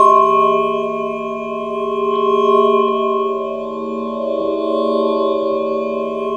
A#3 DRONEB00.wav